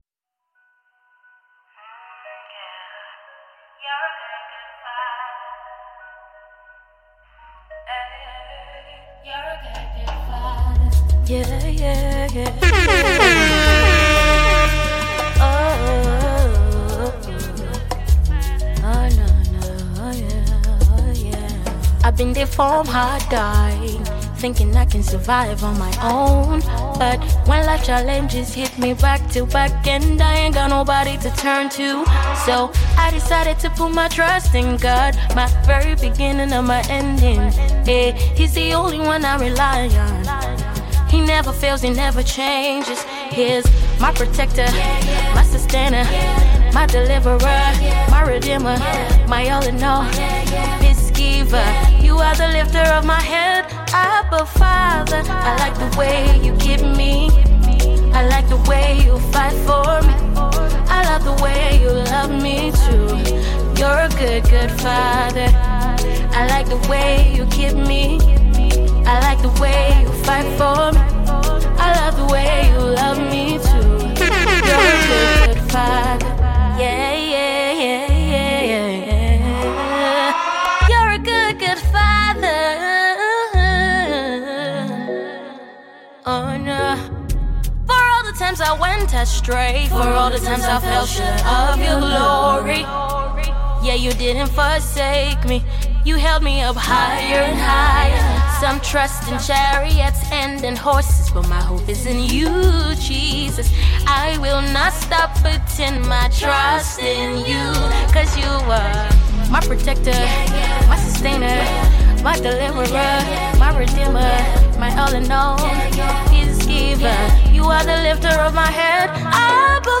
gospel songs on the mix